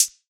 HIHAT (STARGAZING)(1).wav